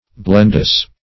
blendous - definition of blendous - synonyms, pronunciation, spelling from Free Dictionary
Blendous \Blend"ous\, a. Pertaining to, consisting of, or containing, blende.